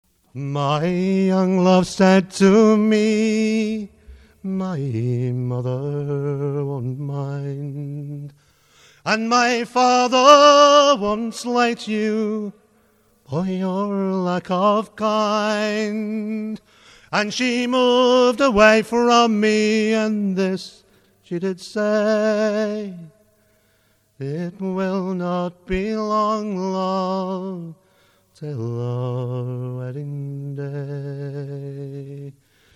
unaccompanied rendition